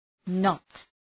knot Προφορά
{nɒt}